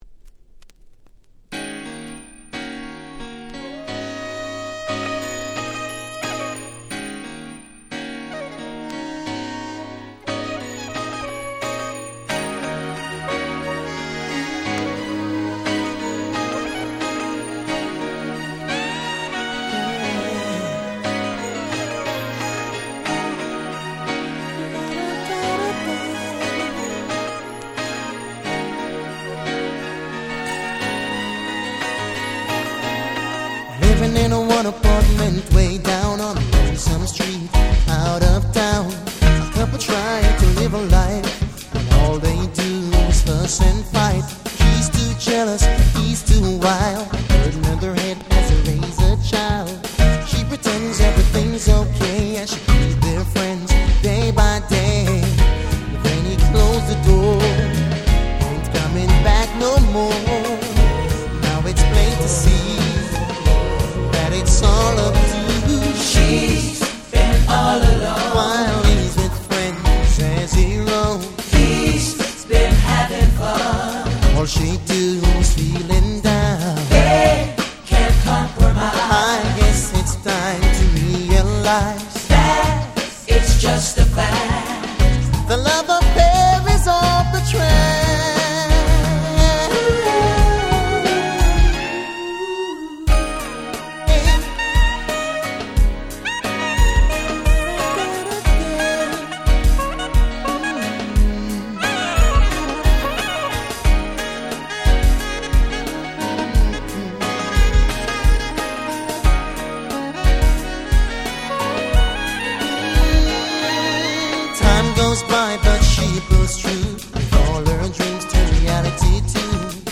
96' Very Nice Reggae R&B !!